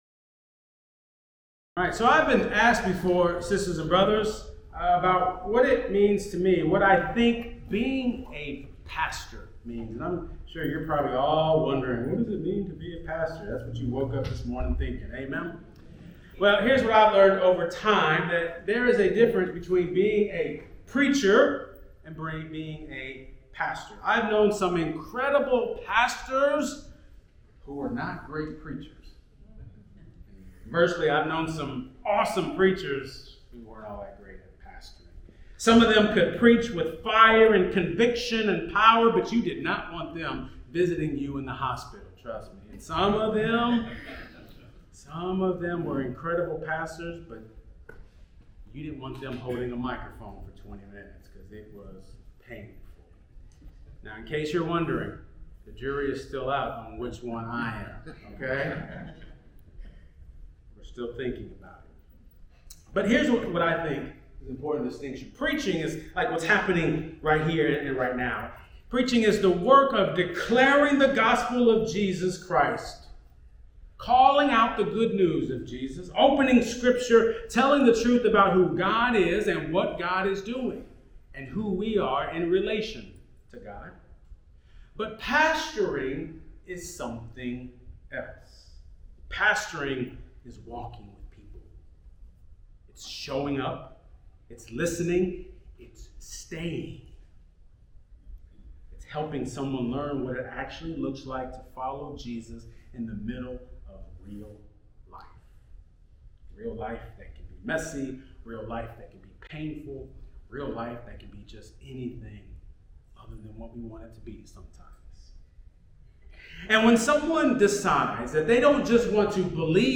Sermons | Kelsey Memorial UMC